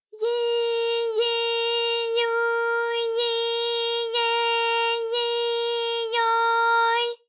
ALYS-DB-001-JPN - First Japanese UTAU vocal library of ALYS.
yi_yi_yu_yi_ye_yi_yo_y.wav